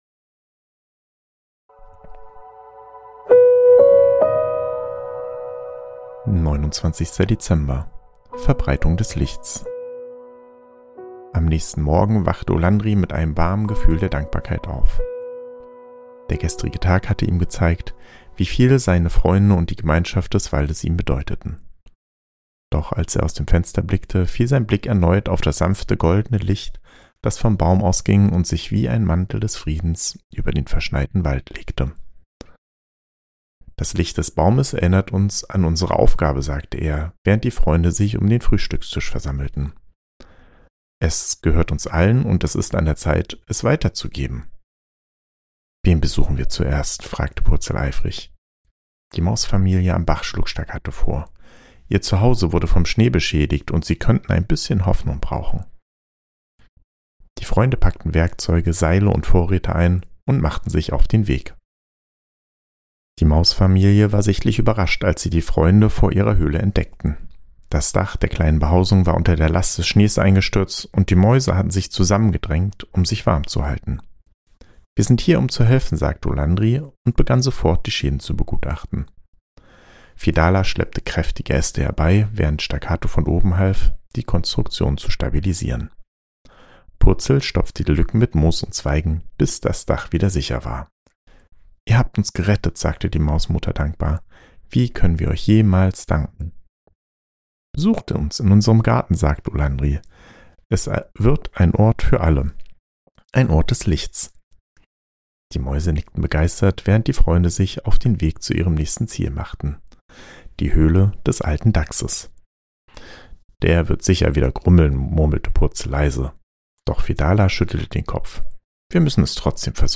Ruhige Adventsgeschichten über Freundschaft, Mut und Zusammenhalt